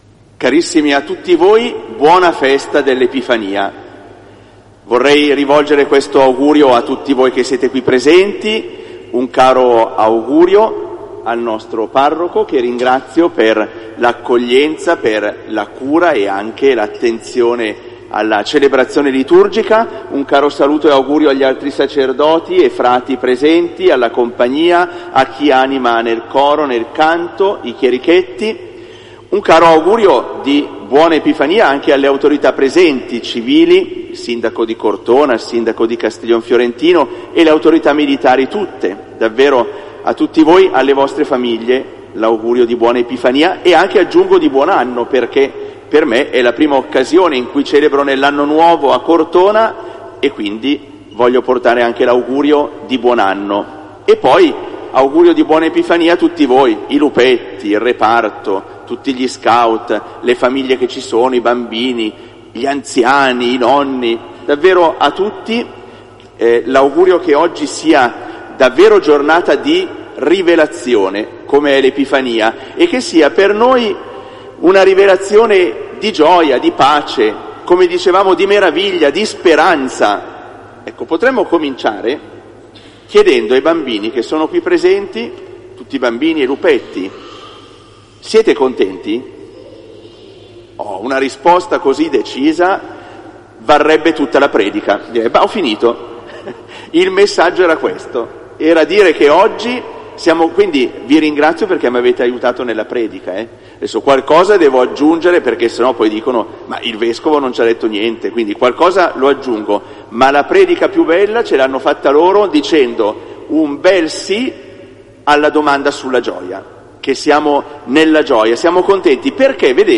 "Omelia del Vescovo" - SS Messa Pontificale 6 gennaio 2025 - Radio Incontri inBlu Cortona